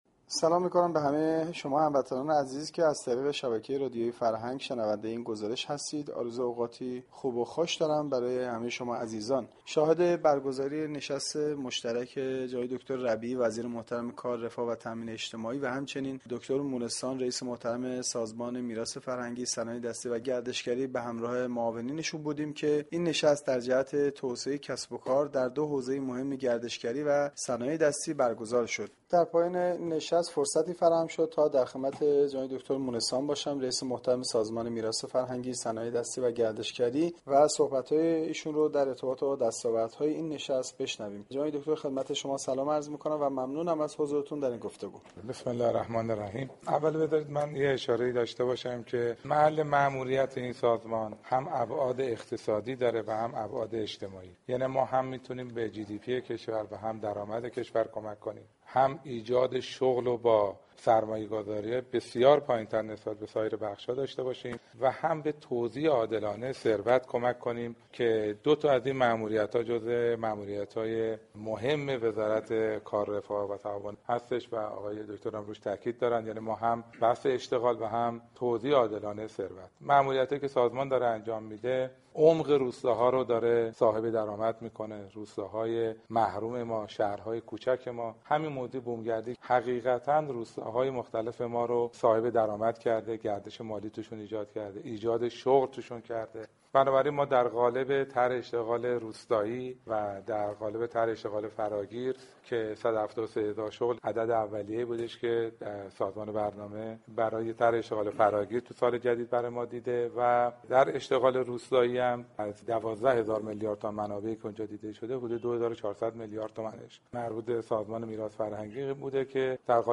دكتر علی اصغر مونسان ریسس سازمان میراث فرهنگی ، صنایع دستی و گردشگری در گفتگوی اختصاصی با گزارشگر رادیو فرهنگ گفت : سازمان میراث فرهنگی ، صنایع دستی و گردشگری از دو بعد اقتصادی و اجتماعی در كشور موثر می باشد به این معنی كه ما هم می توانیم به درآمد كشور كمك كنیم و نیز با سرمایه گذاری پایین نسبت به سایر بخش ها می توانیم ایجاد شغل نماییم .